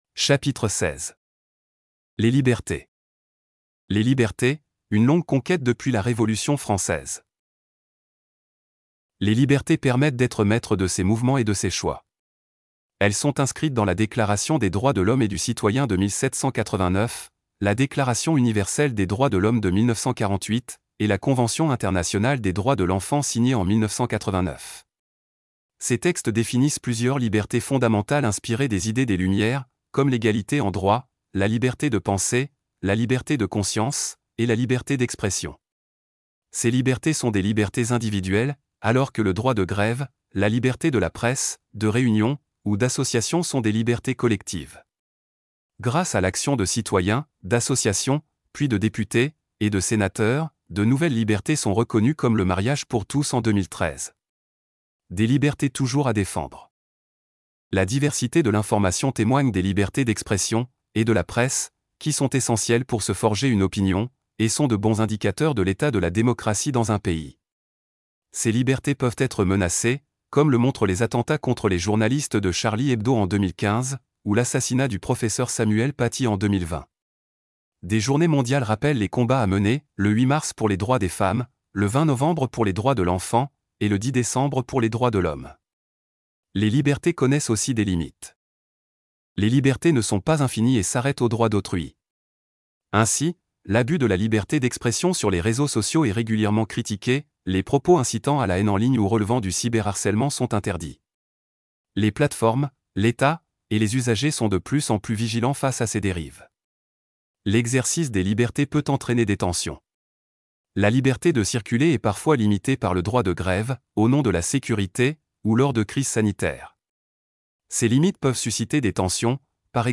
Chap. 16. Cours : Les libertés